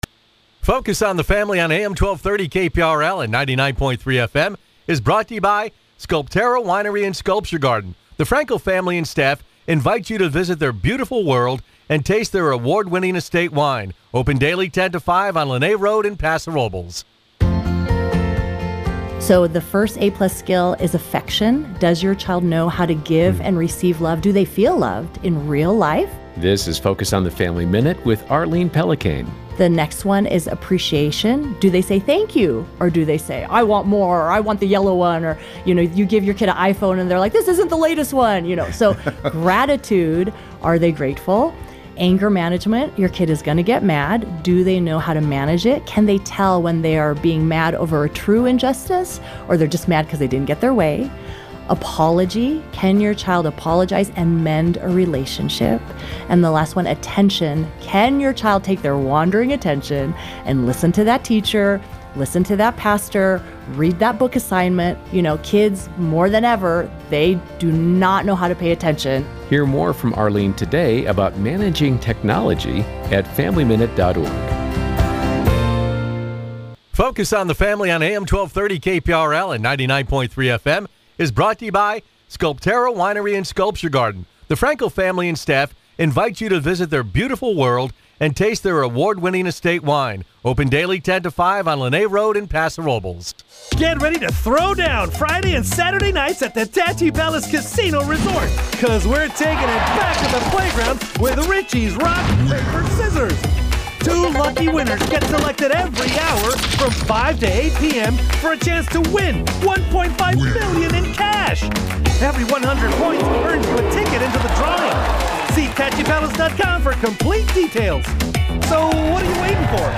Tune in to the longest running talk show on the Central Coast – now in its sixth decade.